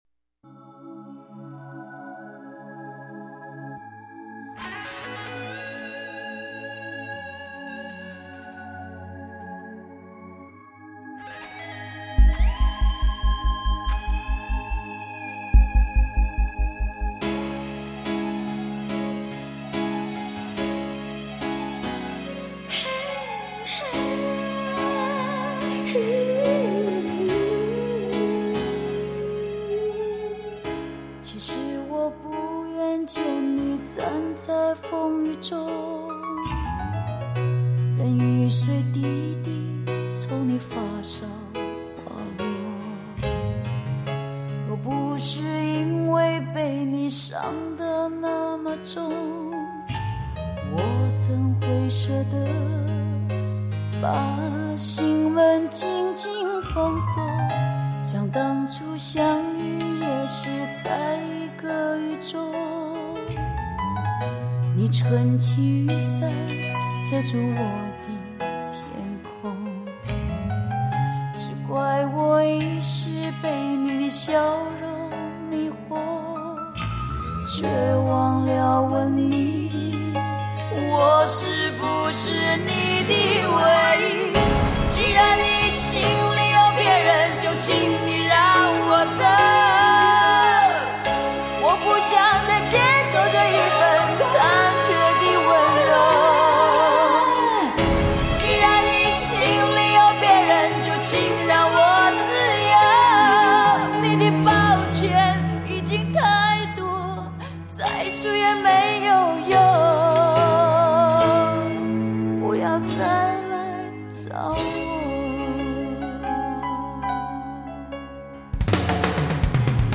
把七情六慾的爱怨缠绵　放在歌声中　把媚惑　野性与热情温柔　贴着音乐走
她的成熟、妩媚、慵懒的独特嗓音可以让人过耳难忘。